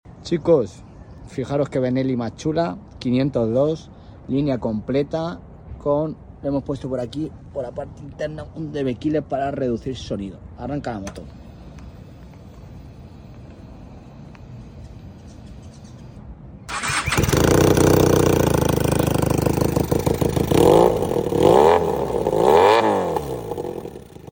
🏍 Finalizamos la semana con esta Benelli 502 potenciada gracias a nuestro exclusivo “Dbkiller Premium” especialmente adaptado para el escape Universal, ¡experimenta un sonido intenso y un increíble petardeo!
🫵🏻 ——————————————— 🏍🏍 We’re wrapping up the week with this Benelli 502 , powered up thanks to our exclusive “Dbkiller Premium” specially adapted for the Universal exhaust, delivering an intense sound and incredible backfire!